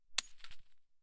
0335ec69c6 Divergent / mods / Bullet Shell Sounds / gamedata / sounds / bullet_shells / generic_leaves_7.ogg 22 KiB (Stored with Git LFS) Raw History
generic_leaves_7.ogg